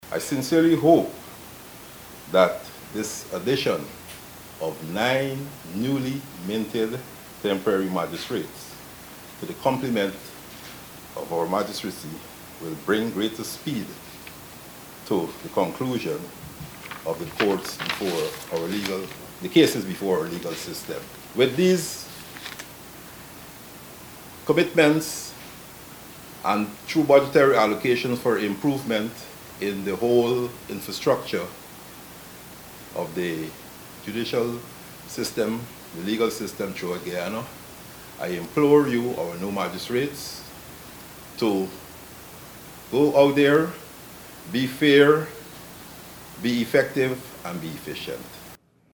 Prime Minister Mark Phillips emphasized the importance of impartiality and efficiency in the magistrates’ role during his address to them.